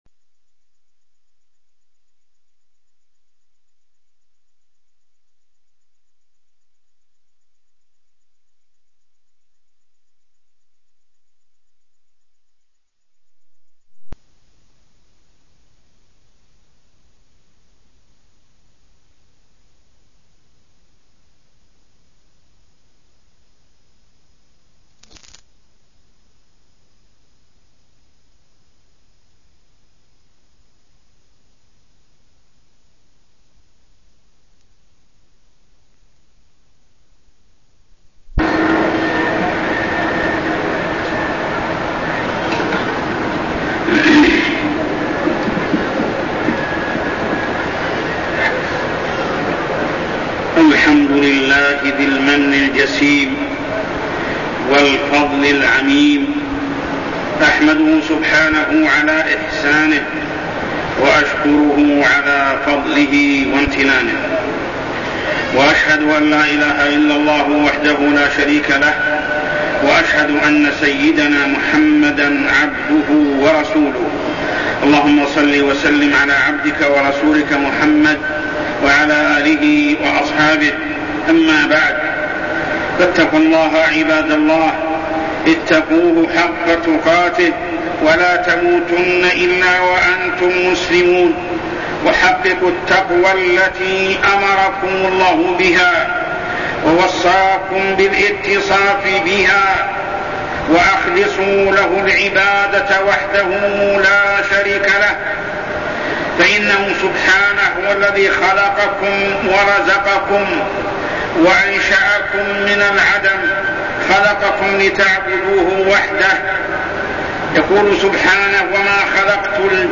تاريخ النشر ٧ ذو الحجة ١٤١٣ هـ المكان: المسجد الحرام الشيخ: محمد بن عبد الله السبيل محمد بن عبد الله السبيل يوم التروية والوقوف بعرفة